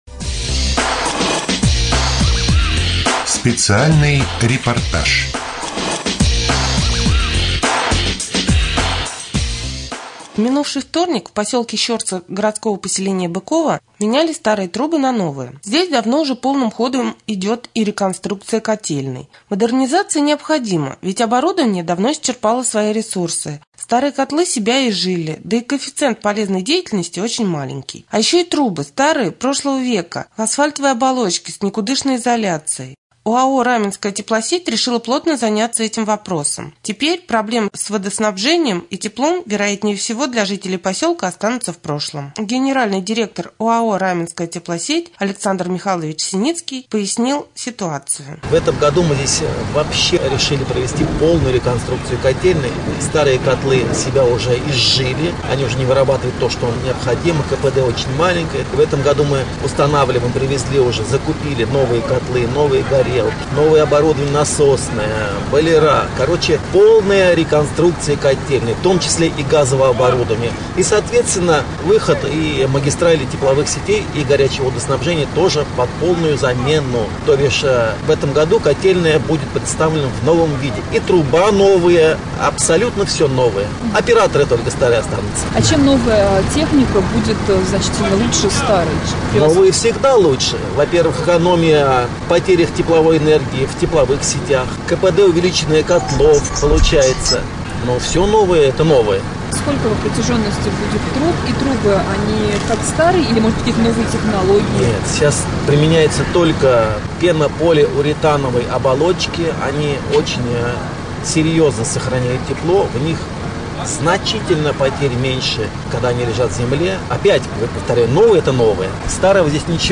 3.Рубрика «Специальный репортаж». В поселке Щорса полным ходом идет замена труб в котельной.